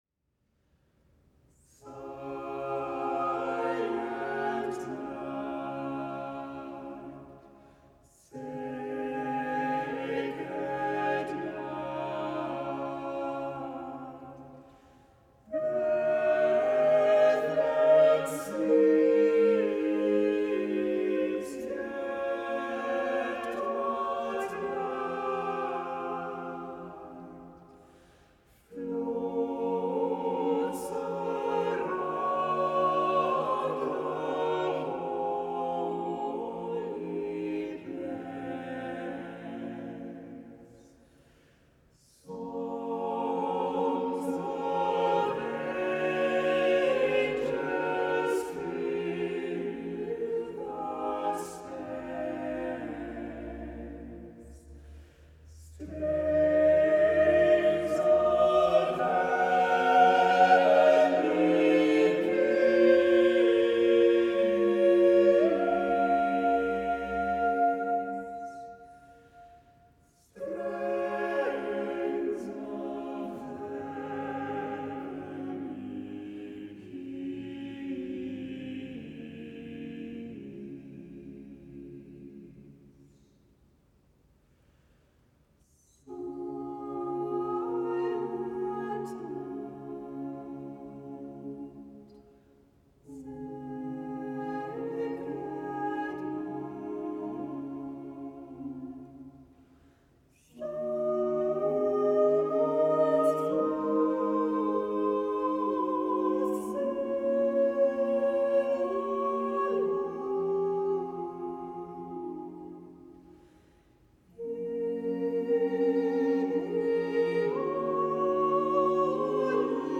On March 11, 2021, the Ensemble Altera Singers recorded several selections at Blessed Sacrament Church in Providence, Rhode Island.
The Ensemble Altera Singers
soprano
alto
tenor
bass
organ